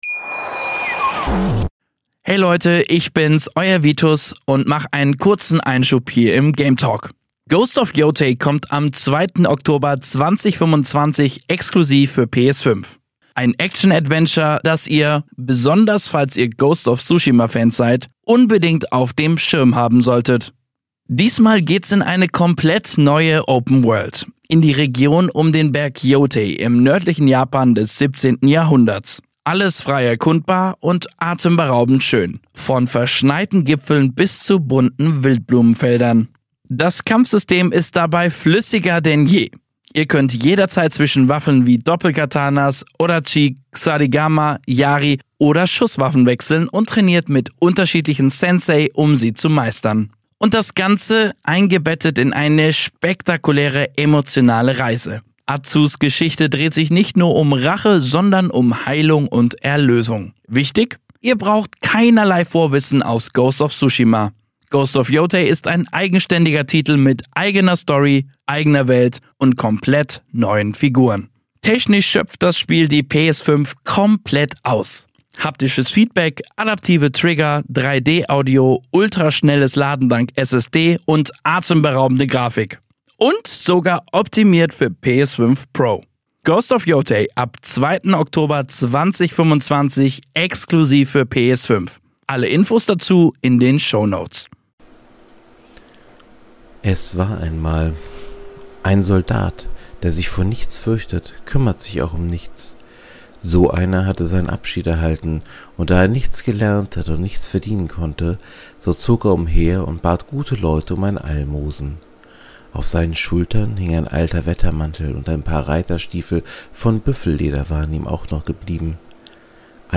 In diesem kleinen Podcast Projekt lese ich Märchen vor. Dabei nutze ich die Texte aus dem Projekt Gutenberg.